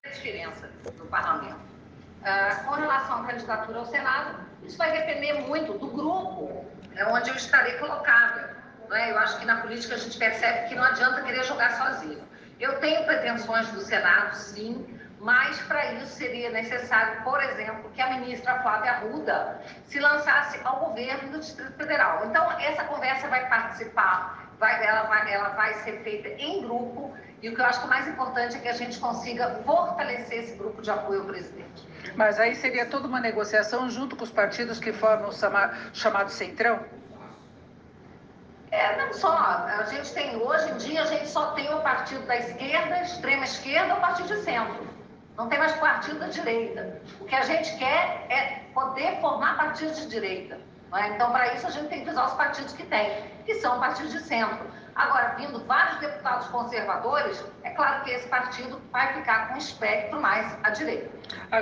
O comentário foi feito nesta noite, durante uma entrevista ao vivo concedida ao Jornal da Gazeta, emissora com sede em São Paulo, capital.